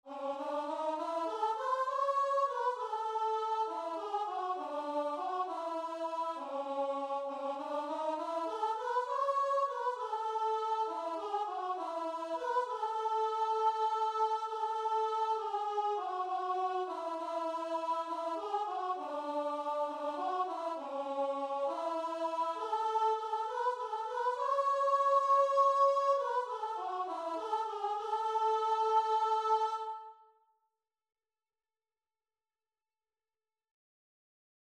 Christian
6/8 (View more 6/8 Music)
Classical (View more Classical Guitar and Vocal Music)